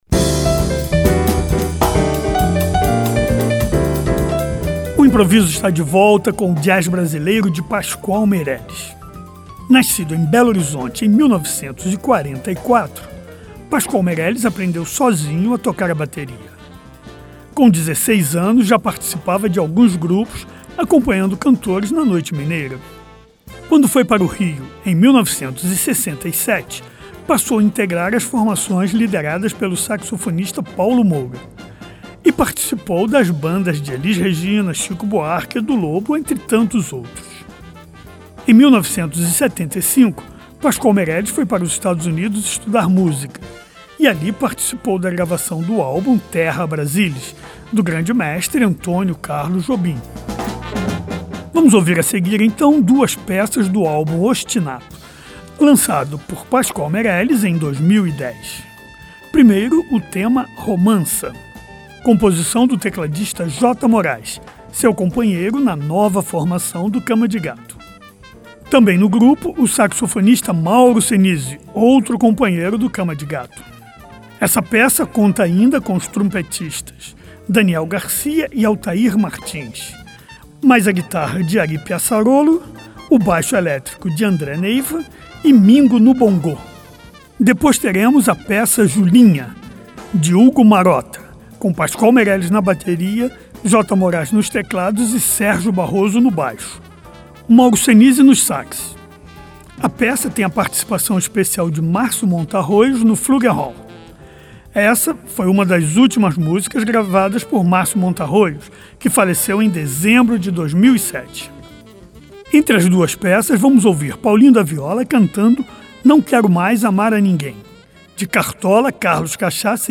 Música Jazz Música Brasileira